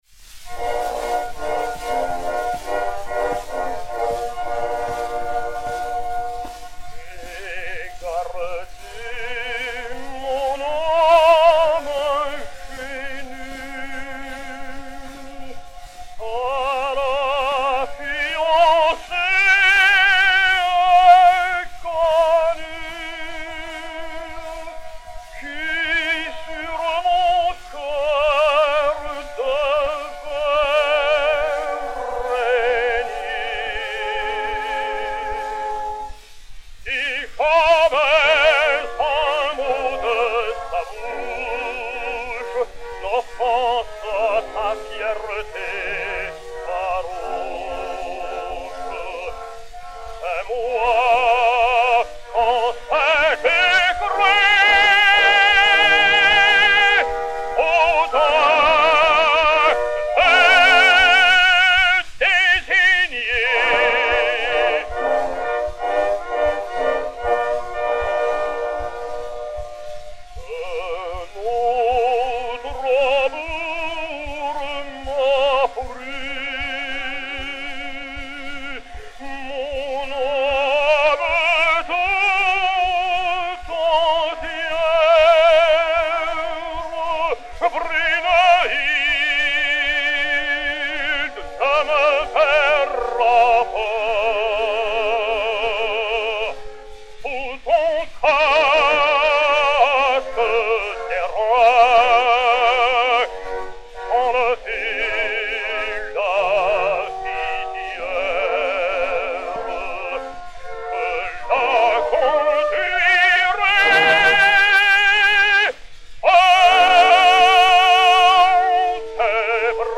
Léon Campagnola (Gérald) et Orchestre
Disque Pour Gramophone 032254, mat. 02487v, réédité sur Y 13, enr. à Paris le 02 juillet 1912